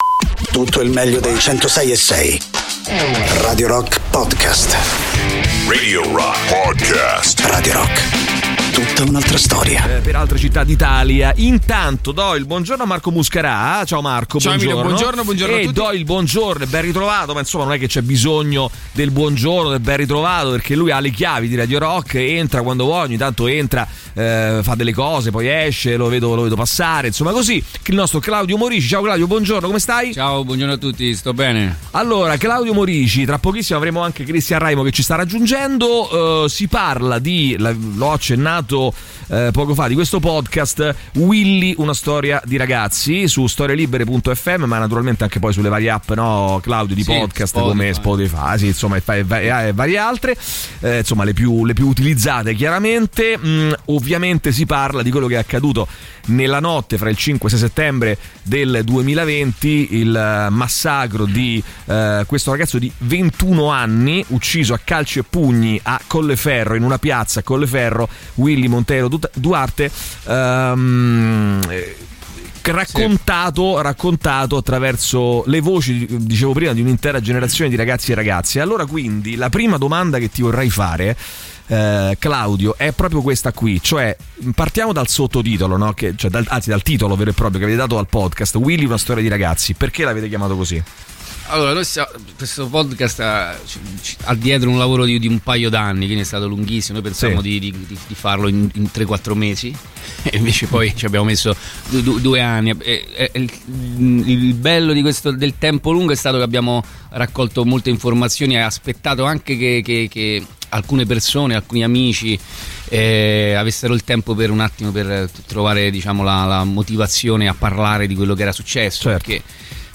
Interviste
ospiti in studio